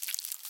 Minecraft Version Minecraft Version snapshot Latest Release | Latest Snapshot snapshot / assets / minecraft / sounds / mob / silverfish / step2.ogg Compare With Compare With Latest Release | Latest Snapshot